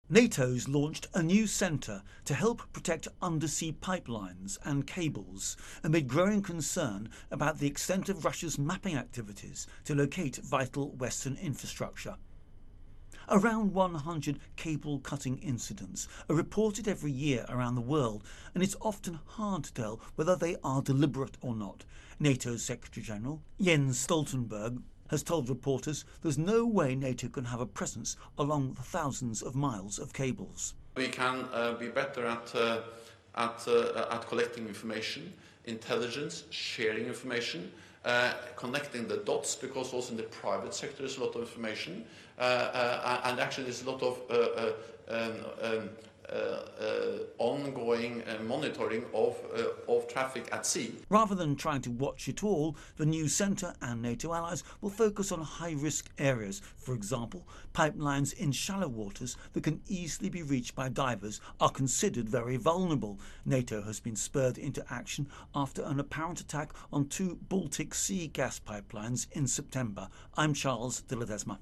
reports on Russia Pipelines.